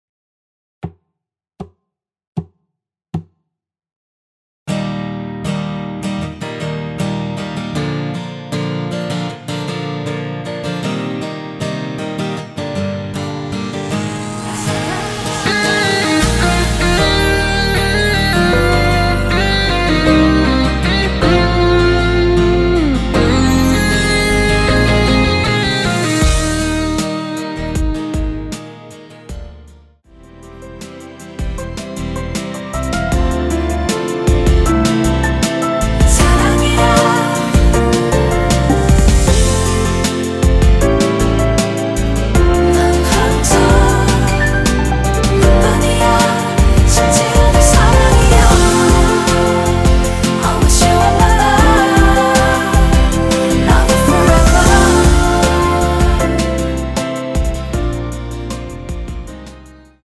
전주 없이 시작하는 곡이라서 노래하기 편하게 카운트 4박 넣었습니다.(미리듣기 확인)
원키에서(-1)내린 코러스 포함된 MR입니다.
Eb
앞부분30초, 뒷부분30초씩 편집해서 올려 드리고 있습니다.